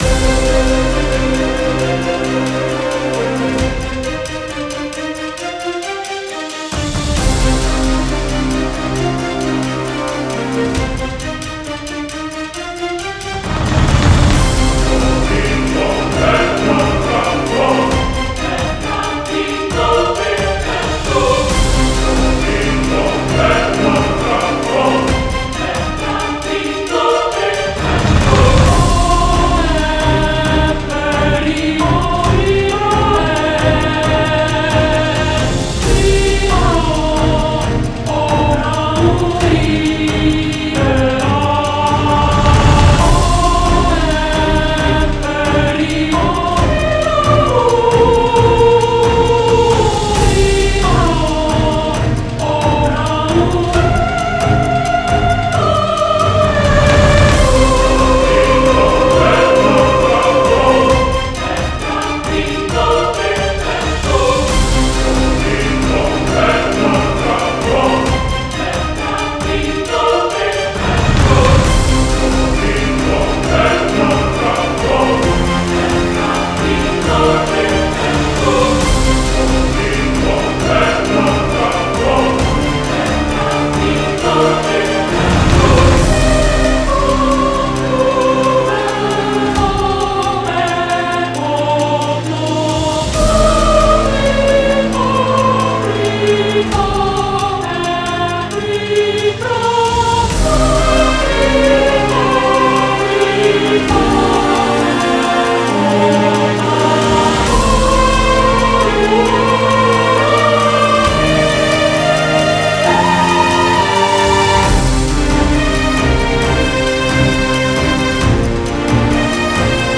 イセカイの破壊の悲しみの歌をイメージして制作しました